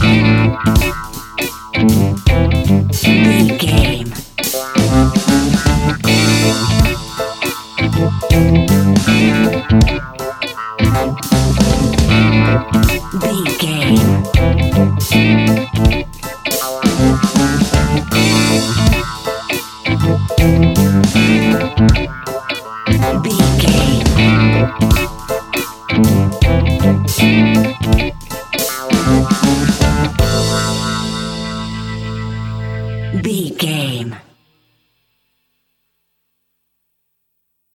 Aeolian/Minor
Slow
dub
instrumentals
laid back
chilled
off beat
drums
skank guitar
hammond organ
percussion
horns